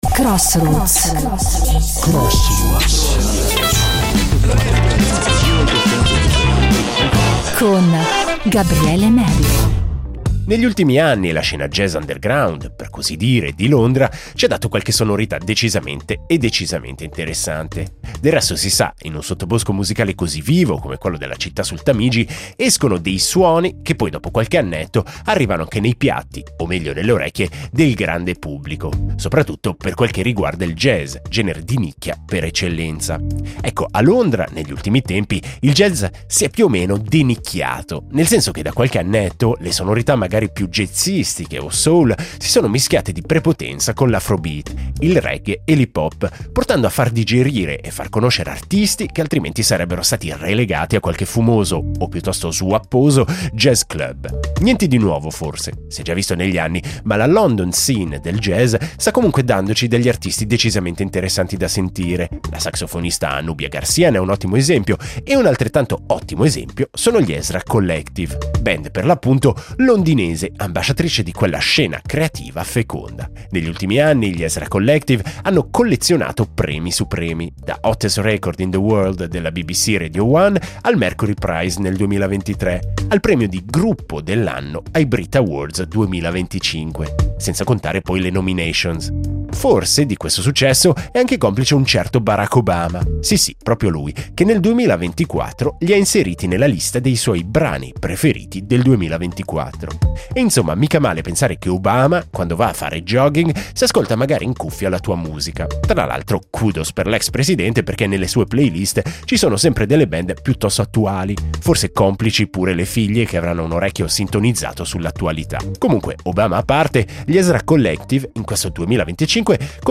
Con un nuovo singolo dalle sonorità caraibiche